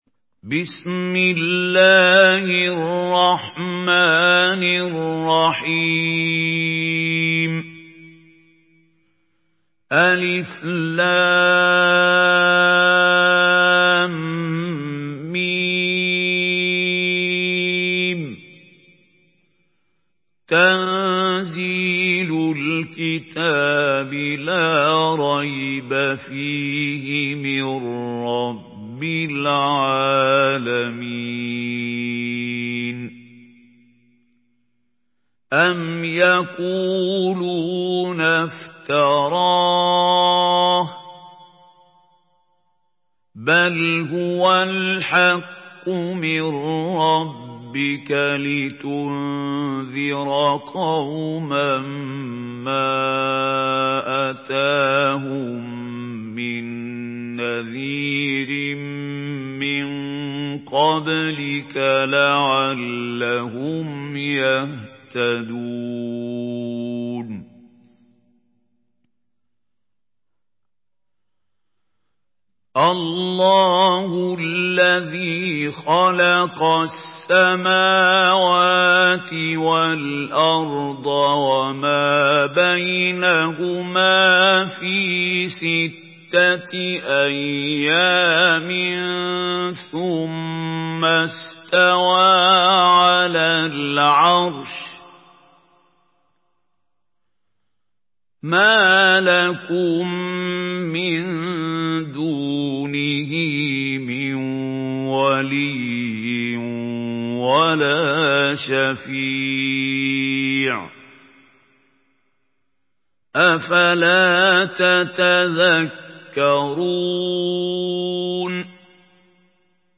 Surah Secde MP3 in the Voice of Mahmoud Khalil Al-Hussary in Hafs Narration
Murattal Hafs An Asim